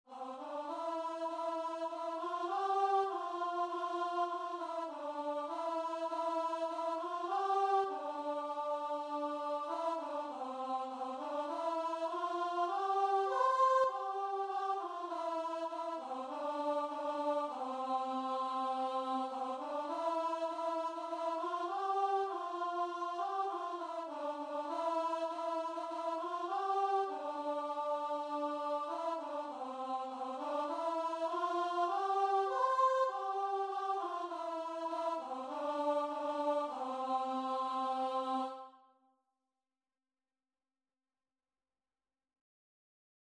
Christian Christian Guitar and Vocal Sheet Music
4/4 (View more 4/4 Music)
Guitar and Vocal  (View more Easy Guitar and Vocal Music)